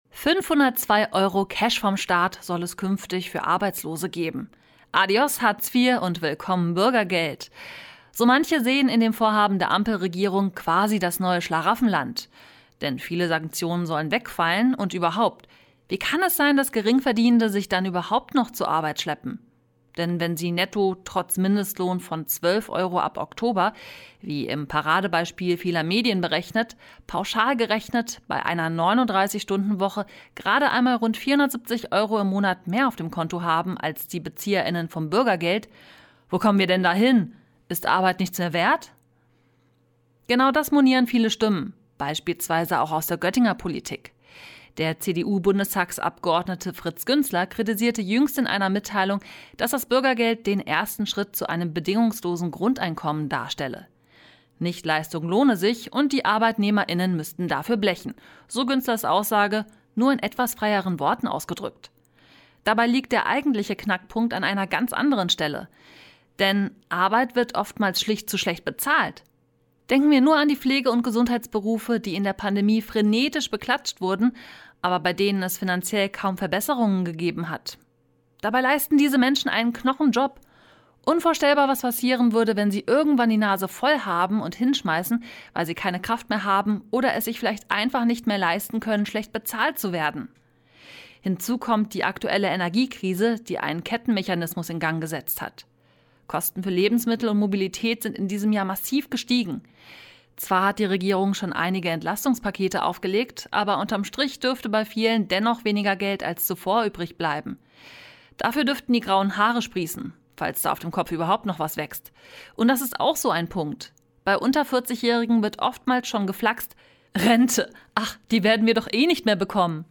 Sendung: Mittendrin Redaktion Kommentar